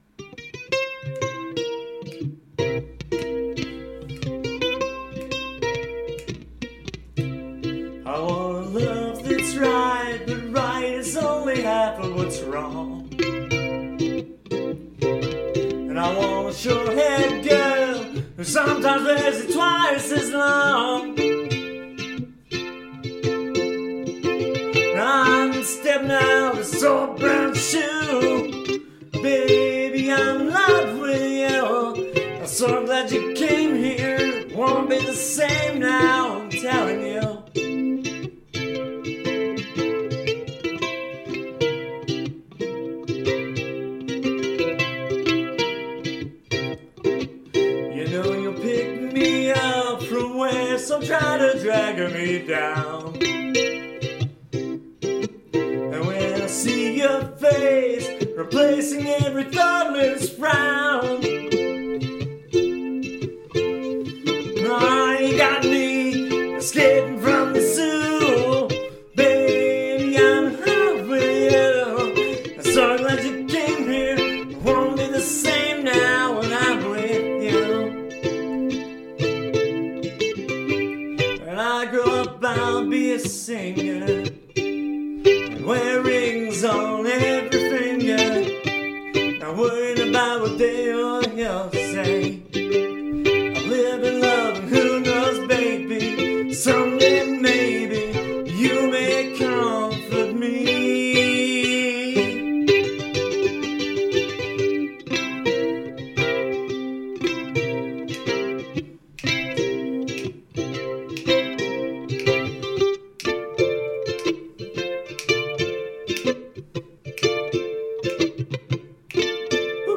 Latest Ukulele Practice Tunes